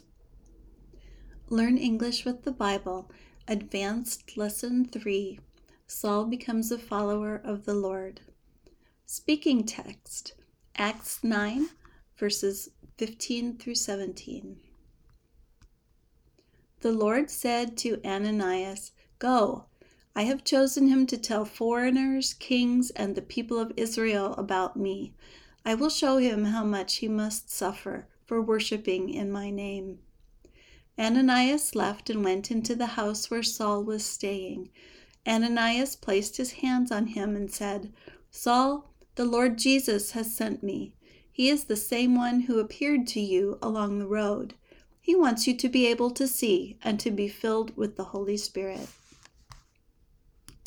Try to imitate the speaker’s intonation, the rhythm of her speech, and the stresses she puts on words and syllables.
Notice the word “foreigner,” with “ei” pronounced as a schwa, or “uh” sound.
Also notice the “eo” in “people” that has the long “e” sound.